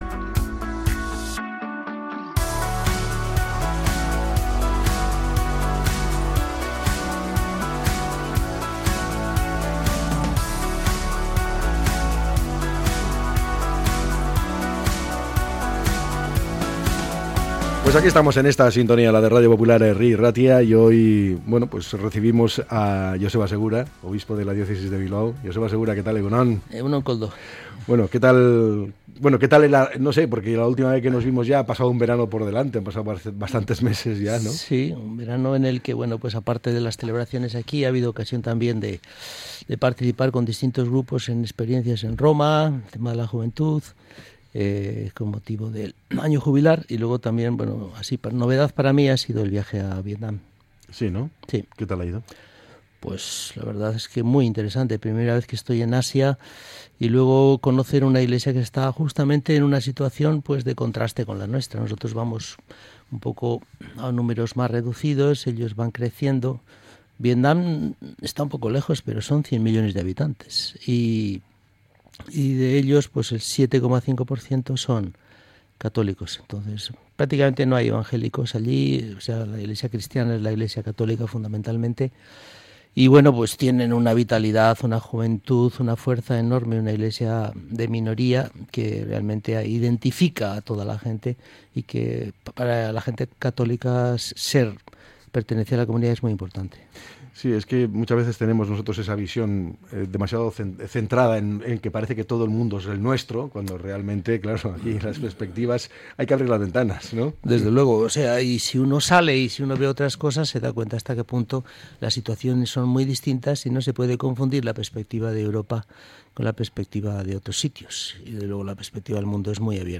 El obispo de Bilbao ha reflexionado sobre el nuevo curso, el conflicto en Gaza y el proyecto de nueva sede diocesana